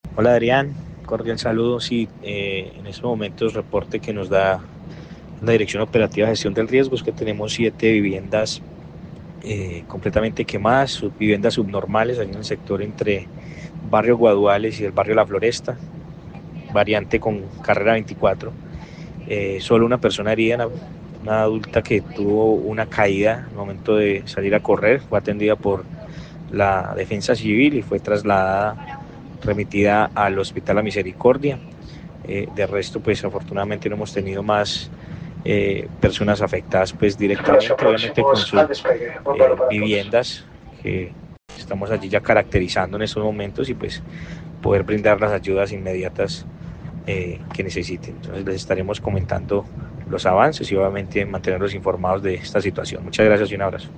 Sebastián Ramos, alcalde de Calarcá